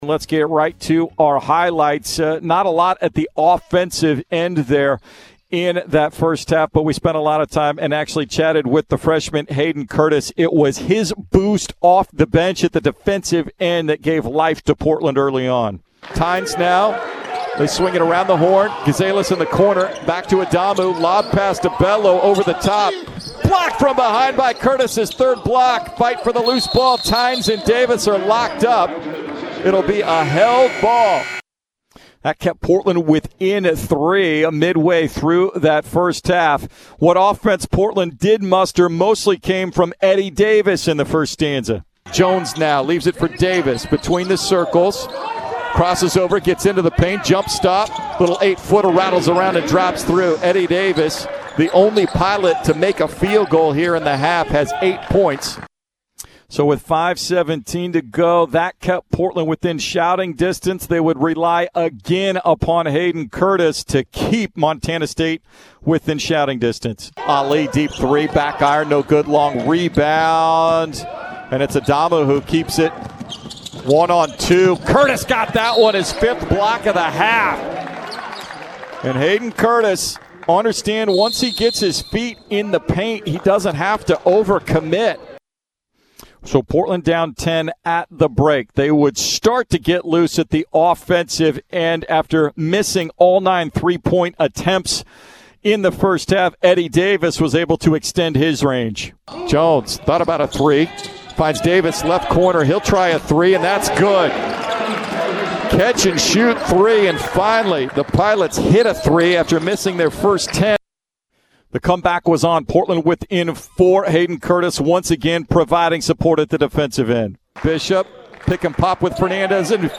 Men's Basketball Radio Highlights vs. Montana State